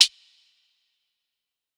Closed Hats
HHAT - MADE.wav